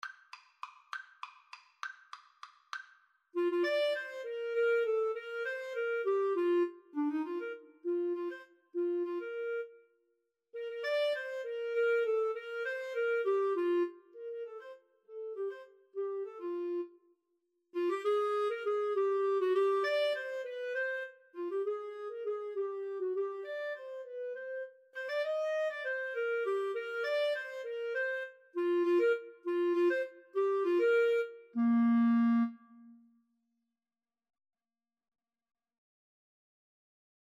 3/8 (View more 3/8 Music)
Classical (View more Classical Clarinet Duet Music)